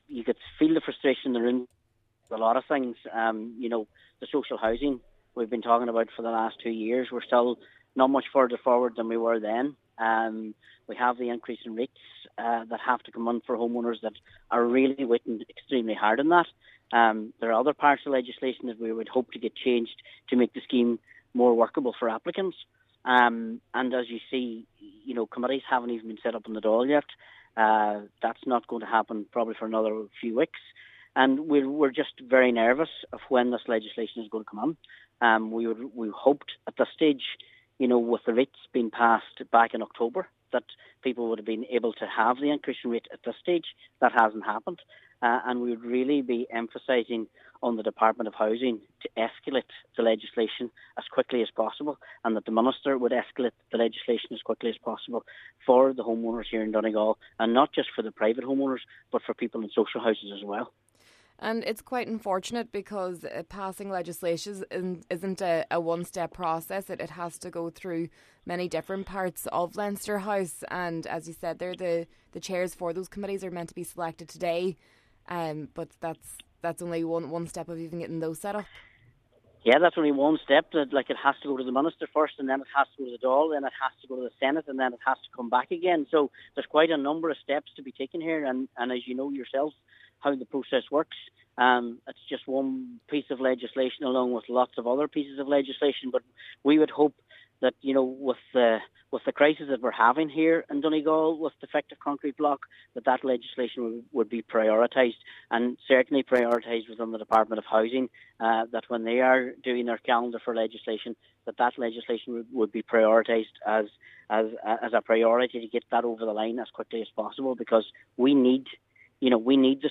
Councillor Martin McDermott says the delay in setting up Oireachtas committees due to the speaking rights row, combined with the Easter recess, has led him to believe that legislation will not complete all the necessary stages to pass in time before the Dáil adjourns for the summer.